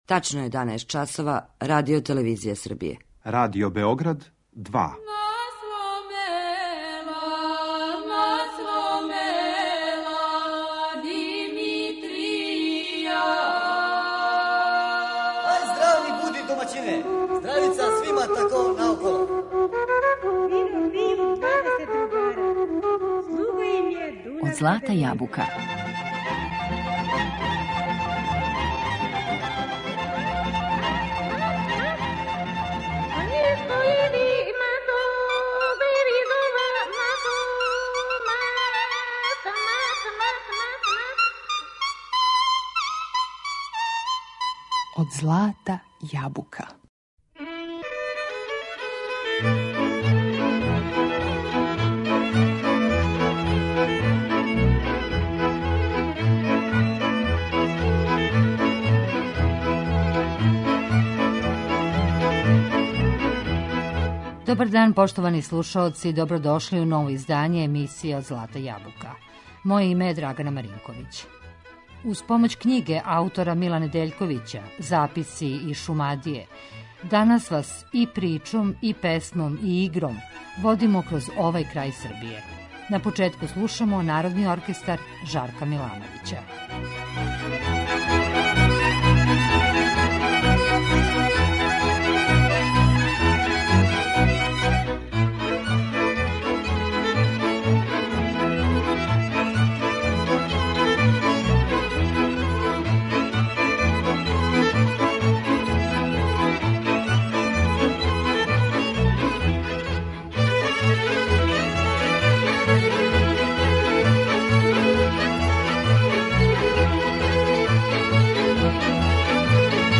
Причом, песмом и игром, водимо вас кроз Шумадију.